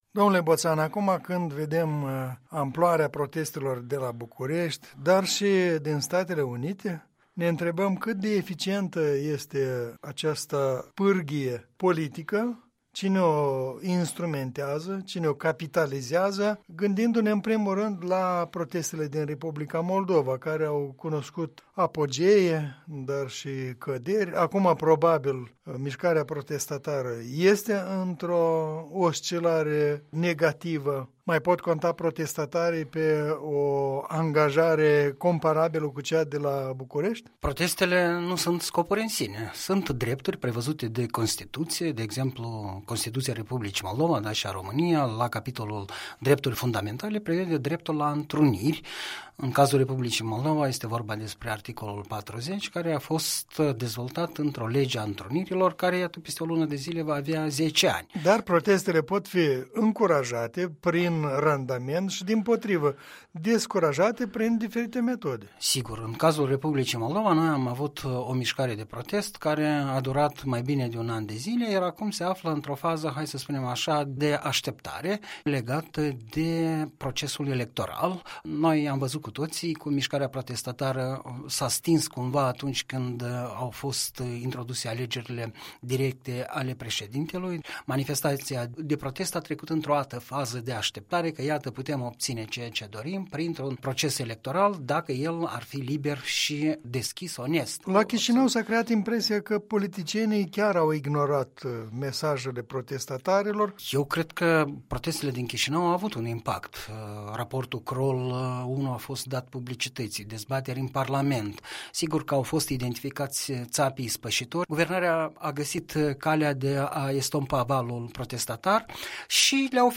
Un punct de vedere săptămânal, în dialog.